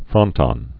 (frŏntŏn, frōn-tōn)